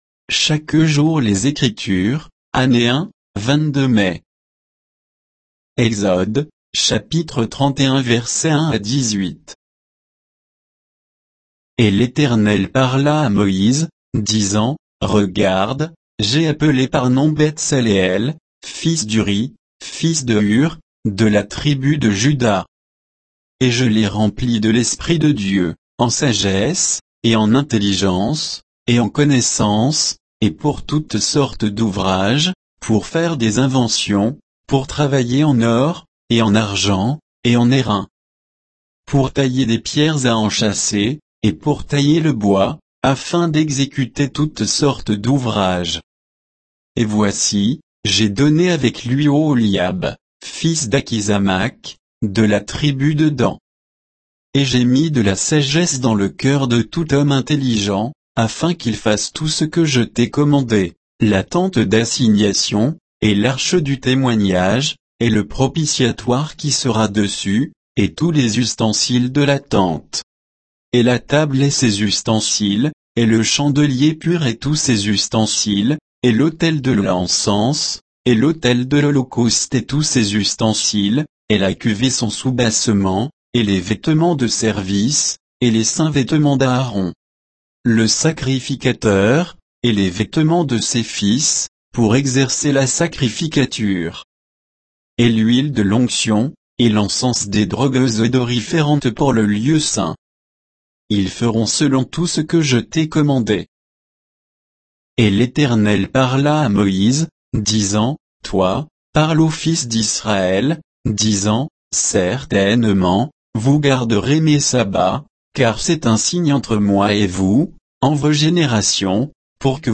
Méditation quoditienne de Chaque jour les Écritures sur Exode 31, 1 à 18